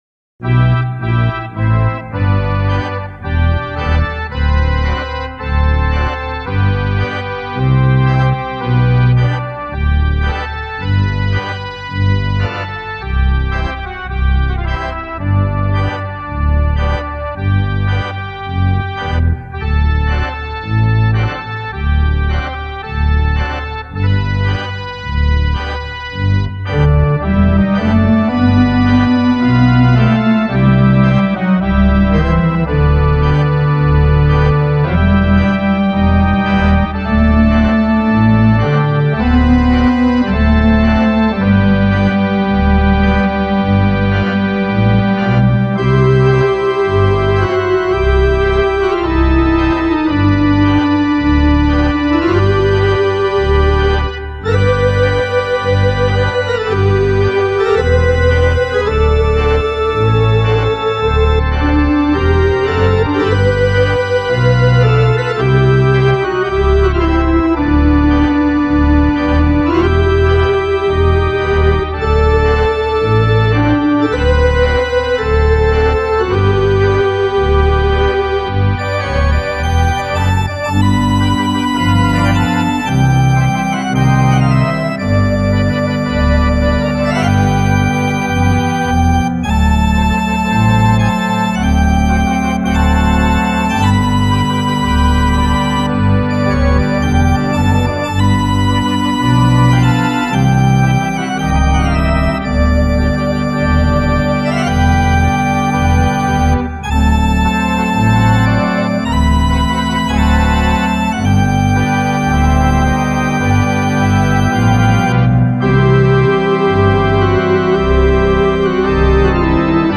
The Mighty MidiTzer at Walnut Hill
These were then mixed down to stereo and edited in Sound Forge to get the proper EQ and reverb in the finished product.
Do Not Pass Me By, a hymn of appeal to God.